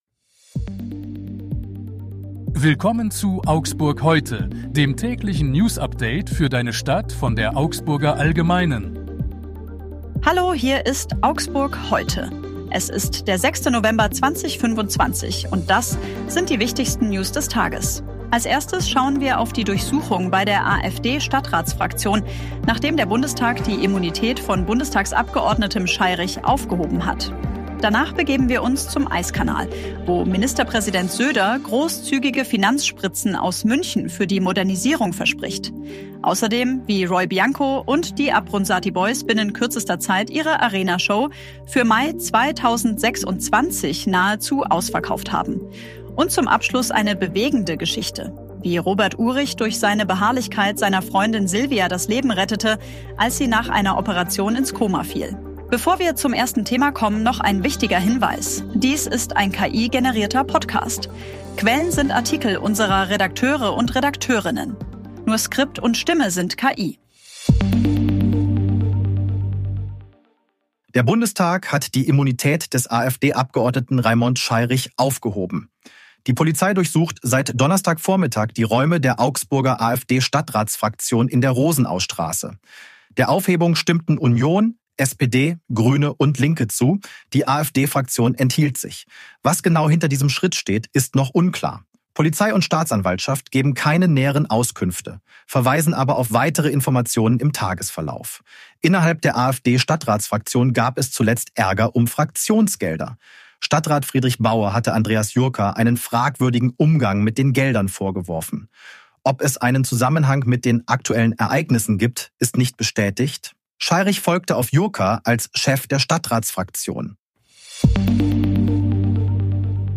Hier ist das tägliche Newsupdate für deine Stadt.